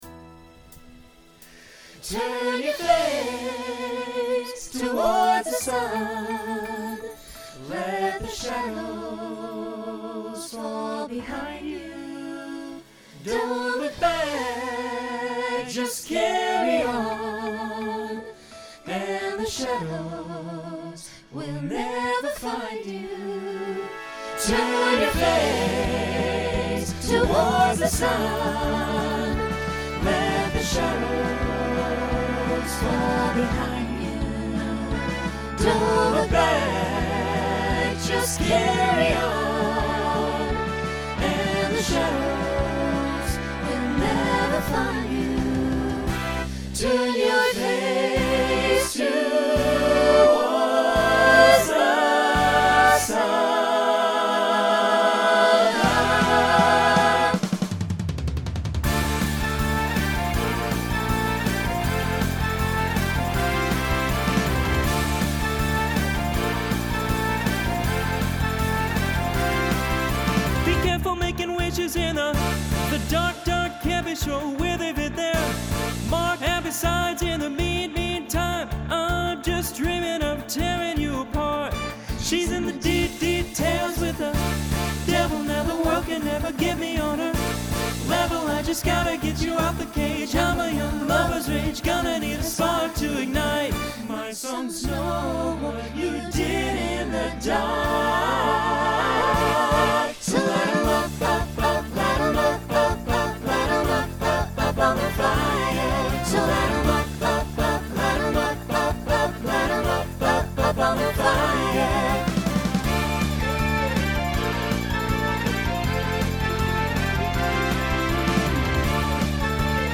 Genre Pop/Dance , Rock Instrumental combo
Opener Voicing SATB